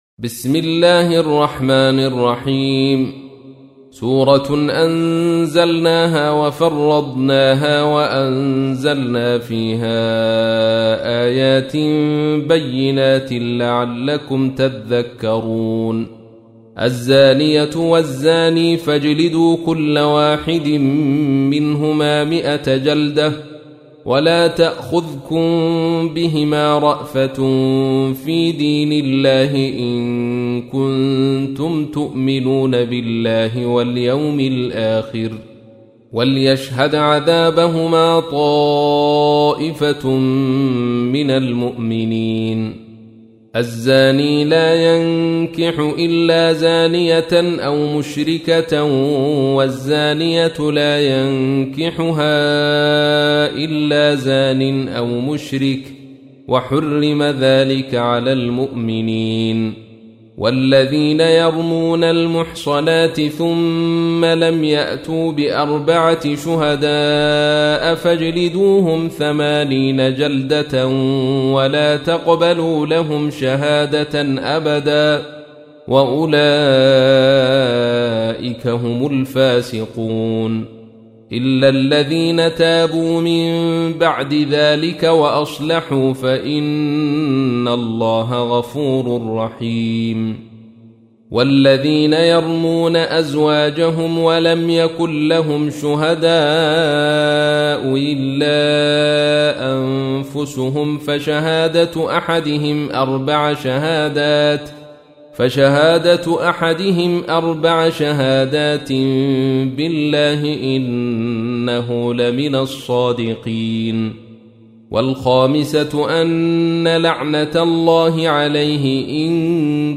تحميل : 24. سورة النور / القارئ عبد الرشيد صوفي / القرآن الكريم / موقع يا حسين